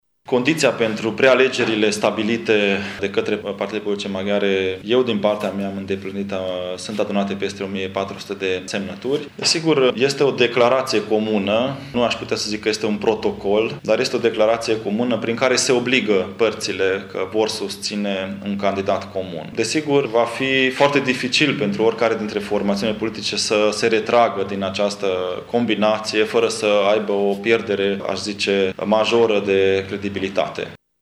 Soos a declarat azi, într-o conferință de presă, că, dacă vreuna din cele trei formaţiuni se va dezice de declaraţia de intenţie, va pierde din credibilitate: